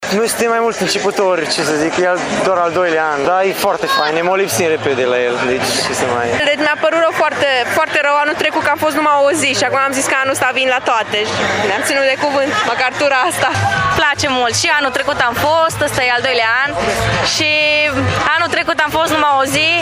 Spectatorii au apreciat concertele, iar unii dintre ei au venit în fiecare seară: